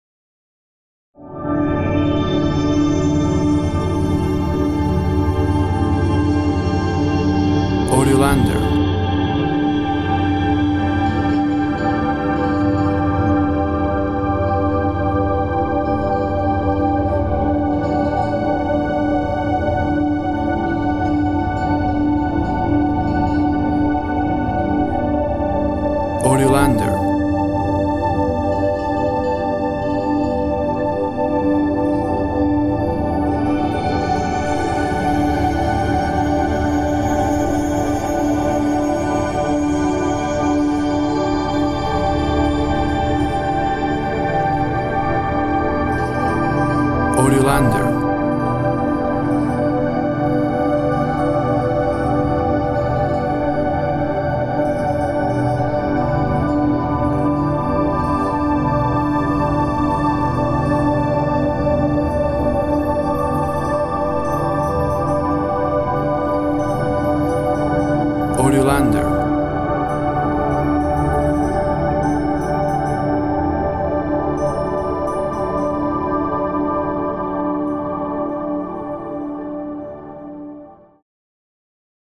WAV Sample Rate 24-Bit Stereo, 44.1 kHz
Tempo (BPM) 120